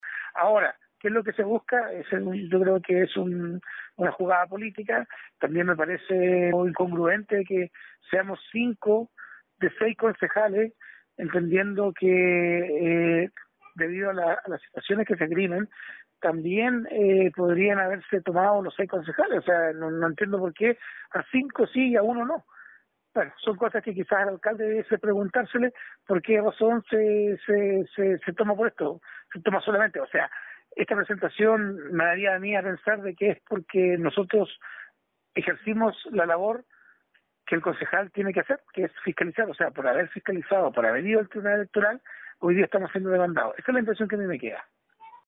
Por su parte el concejal Andrés Ibáñez señaló que le parece muy extraño que la acusación solo sea para el grupo de cinco ediles y no alcance a involucrar a Pedro Naguil.
11-ACUSACION-CONCEJAL-ANDRES-IBANEZ.mp3